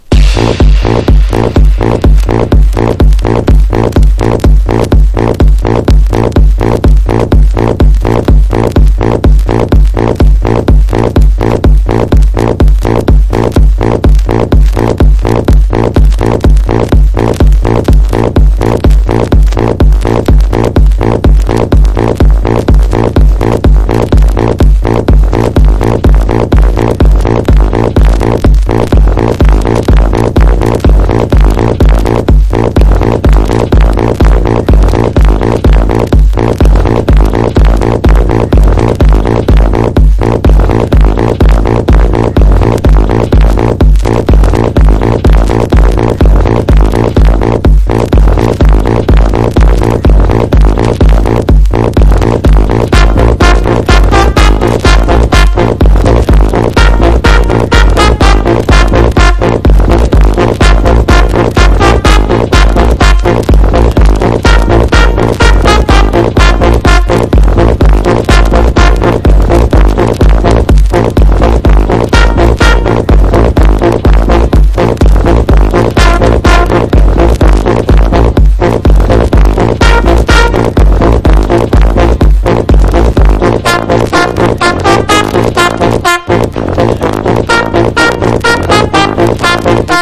ELECTRO HOUSE / TECH HOUSE